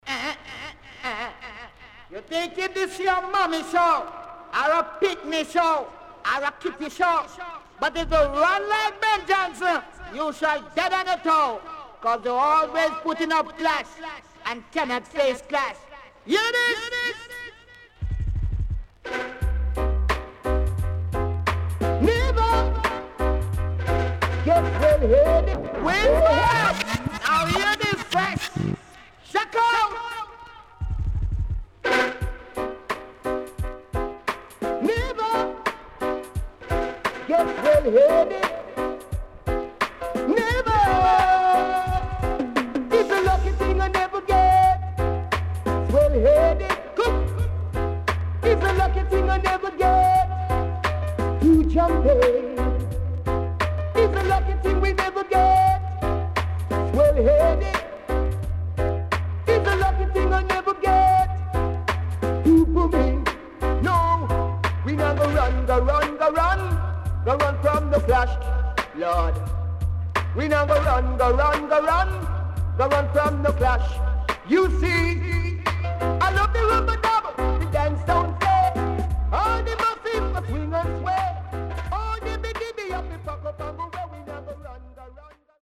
HOME > REISSUE USED [DANCEHALL]